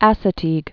(ăsə-tēg)